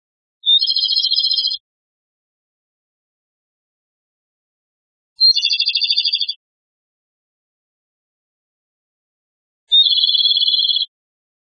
〔コマドリ〕ピッピッ／チーカララ････（さえずり）／高山のササなどの密生した林に
komadori.mp3